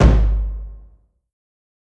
KICK_BOMBS.wav